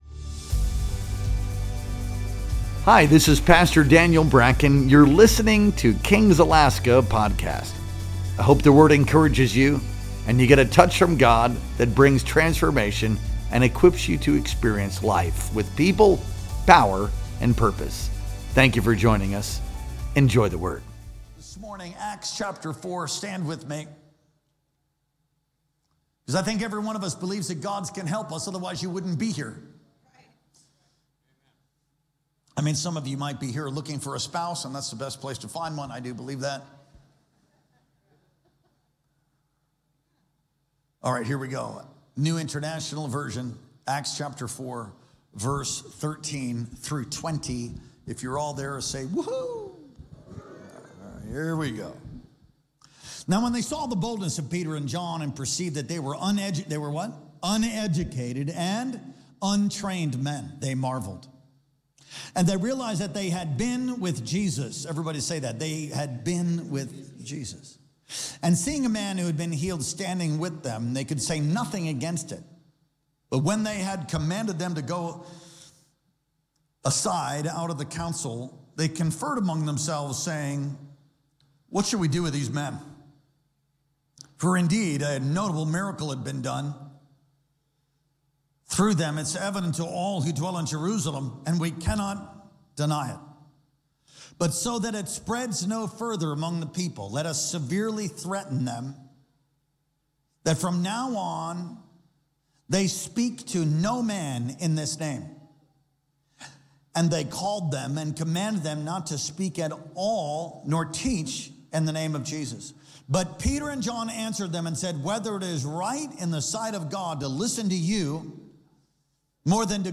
Our Sunday Worship Experience streamed live on July 27th, 2025.